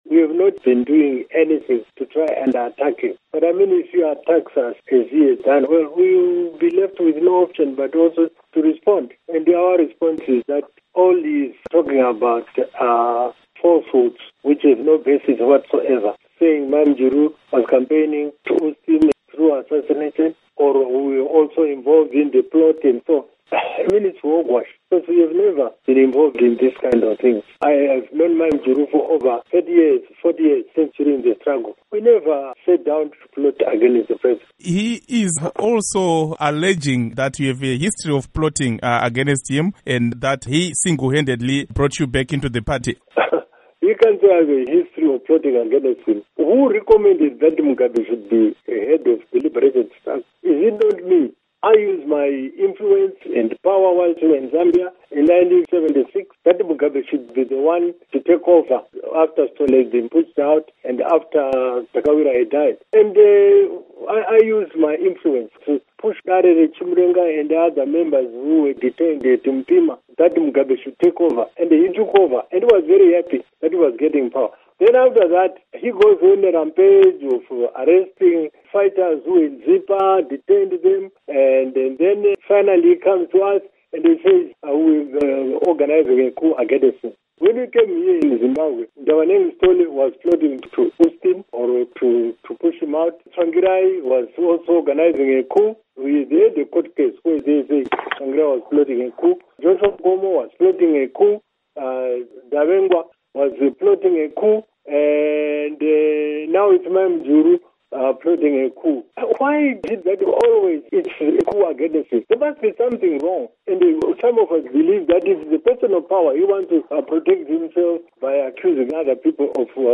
Interview With Rugare Gumbo on Mugabe Birthday Bash Remarks